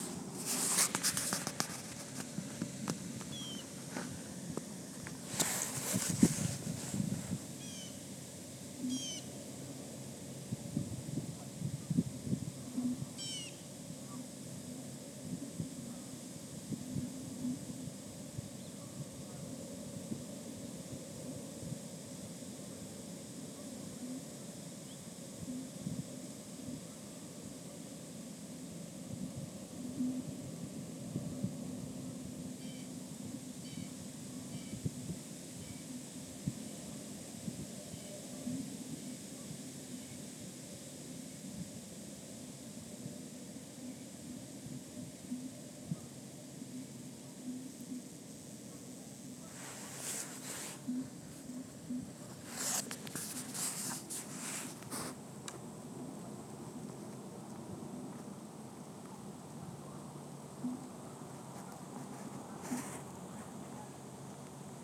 I just love the look of this photo of a bench at the city park here in Madisonville, Ky.
Sitting there I could hear the sounds of geese and ducks off to the left out of the frame making their quiet sounds as they were roosting on the the ice, but there was another sound that had caught my attention. It was the sound of the warmer water from the lake as it reacted on the ice on the surface. I’d not heard this before and it was almost like a musical instrument as it was coming from all over the lake, almost like an orchestra when added to the other sounds of nature.
Below is a recording I did with my iPhone but it really doesn’t do the experience justice.
Lake-Sounds.wav